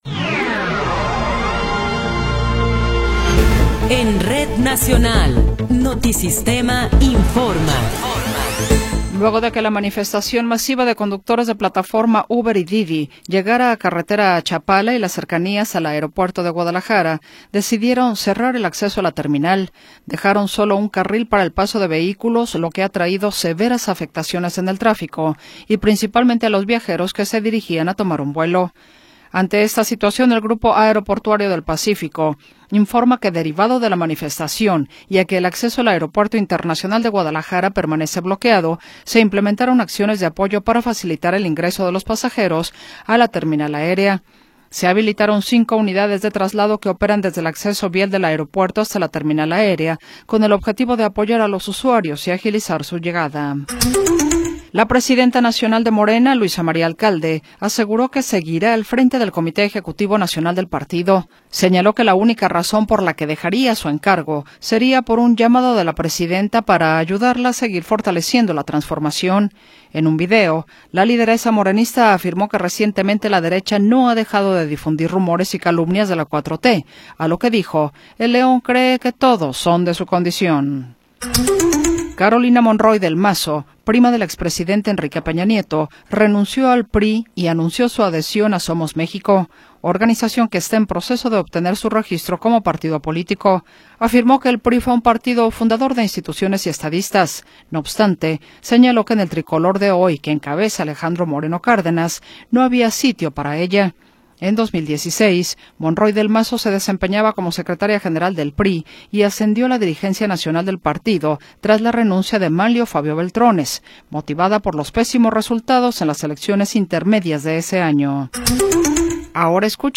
Noticiero 19 hrs. – 14 de Abril de 2026
Resumen informativo Notisistema, la mejor y más completa información cada hora en la hora.